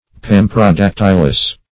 Search Result for " pamprodactylous" : The Collaborative International Dictionary of English v.0.48: Pamprodactylous \Pam`pro*dac"tyl*ous\, a. [Pan- + Gr. pro` forward + da`ktylos finger.]
pamprodactylous.mp3